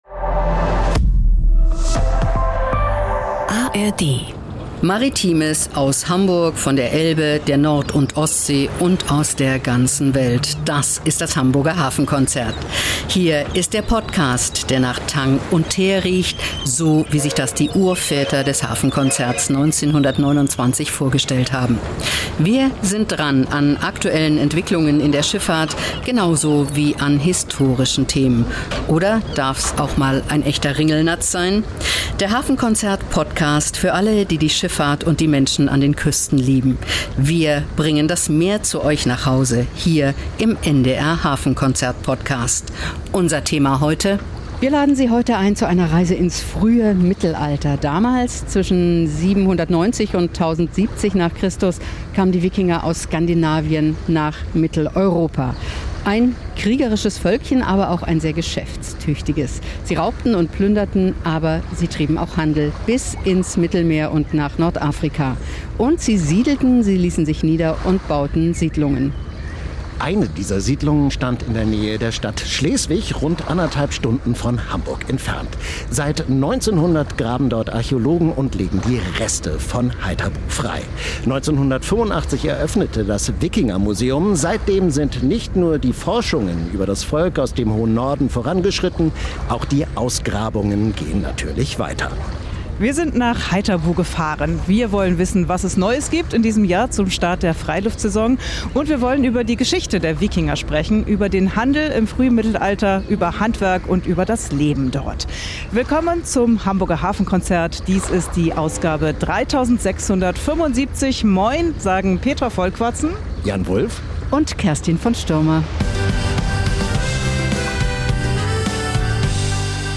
Spannende Reportagen und exklusive Berichte rund um den Hamburger Hafen, die Schifffahrt und die norddeutsche Geschichte.